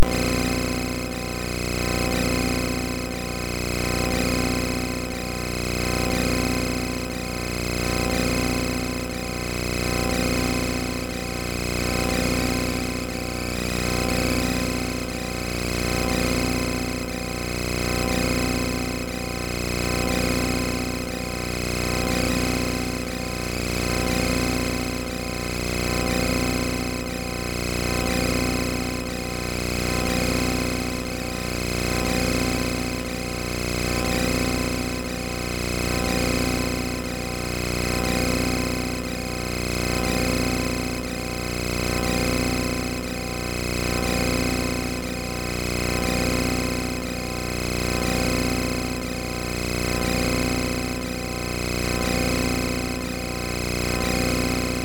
system_malfunction.mp3